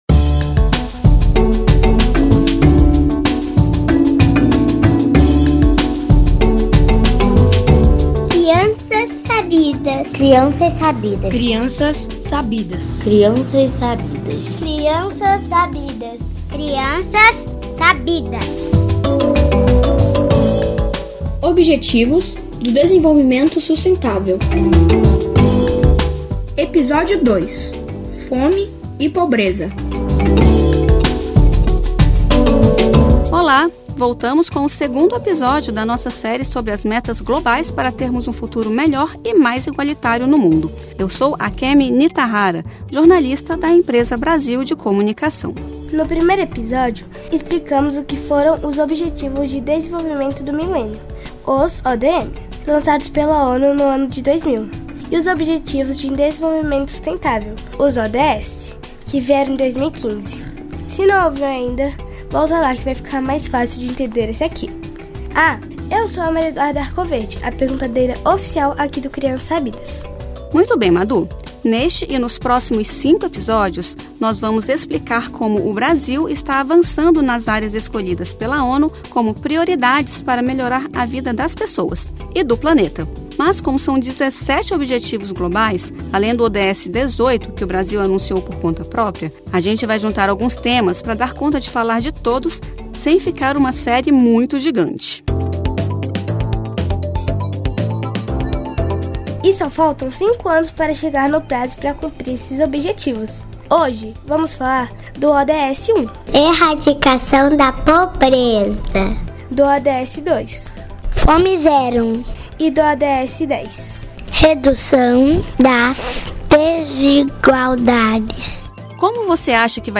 O episódio foca nos ODS 1 (Erradicação da pobreza), 2 (Fome zero) e 10 (Redução das desigualdades). A série explica, de forma didática e com participação de crianças, asmetas globais definidas pela Organização das Nações Unidas para melhorar a qualidade de vida da população até 2030.